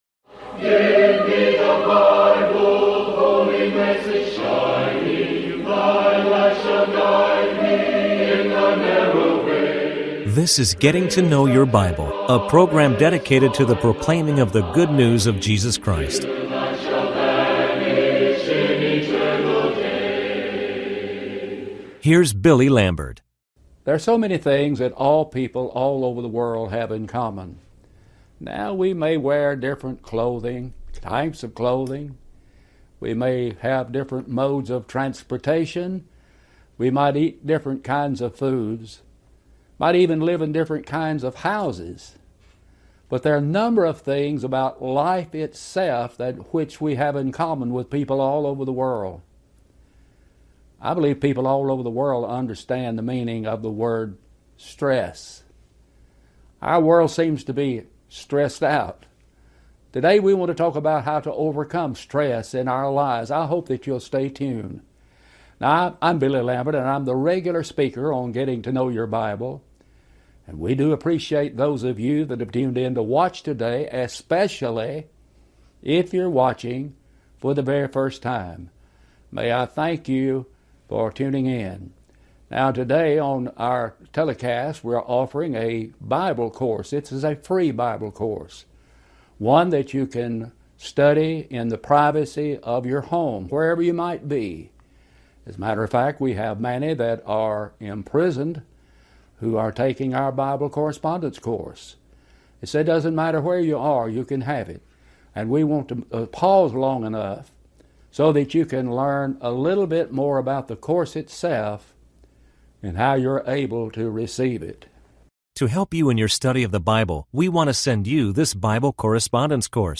Talk Show Episode, Audio Podcast, Getting To Know Your Bible and Overcoming Stress - ep1474 on , show guests , about Overcoming Stress, categorized as History,Kids & Family,Religion,Christianity,Society and Culture